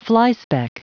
Prononciation du mot flyspeck en anglais (fichier audio)
Prononciation du mot : flyspeck